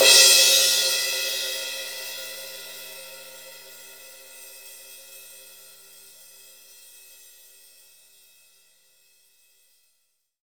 Index of /90_sSampleCDs/Roland L-CD701/KIT_Drum Kits 2/KIT_Dry Kit
CYM ROCK 0QR.wav